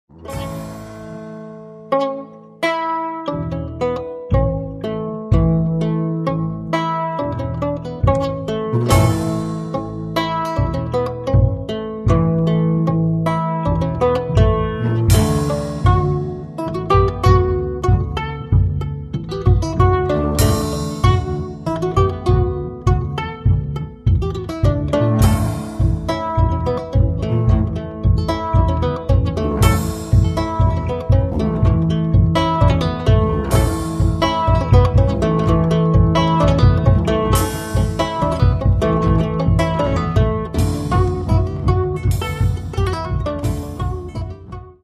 Каталог -> Другое -> Барды